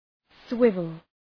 Shkrimi fonetik {‘swıvəl}